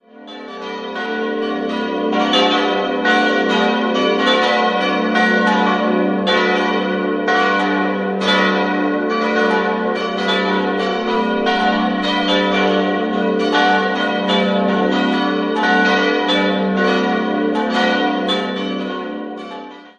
Idealquartett fis'-a'-h'-d'' Die zweitgrößte Glocke dürfte noch aus spätgotischer Zeit stammen, alle anderen goss 1951 Rudolf Hofweber in Regensburg.